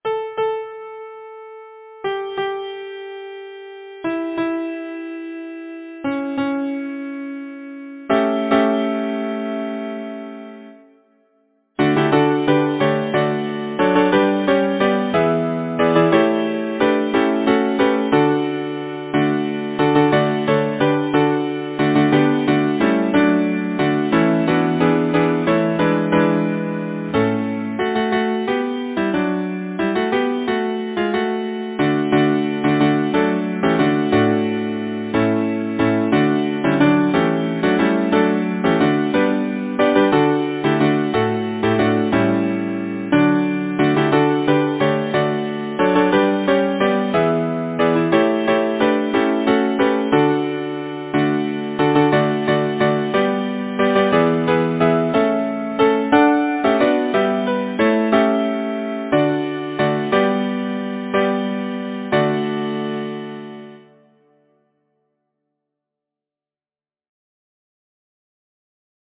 Title: Let us up and away Composer: John Winans Shryock Lyricist: Sophronia Irwin ? Number of voices: 4vv Voicing: SATB Genre: Secular, Partsong
Language: English Instruments: A cappella